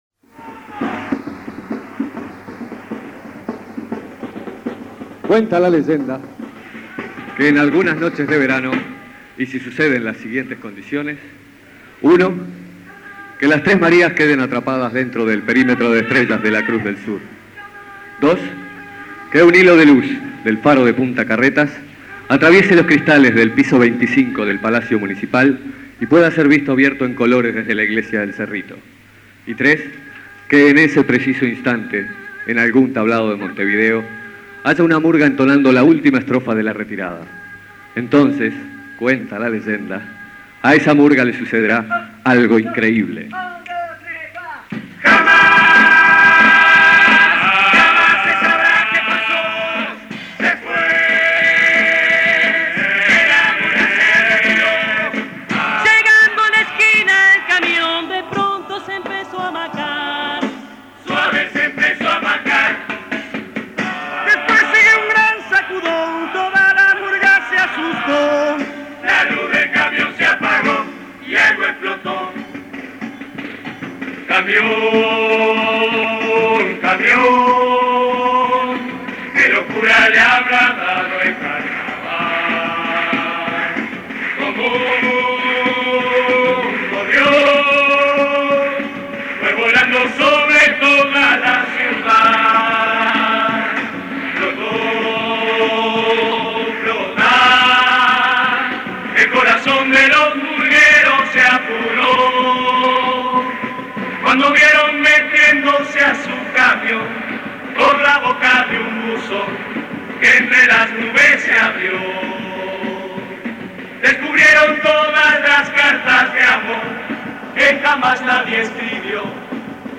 retirada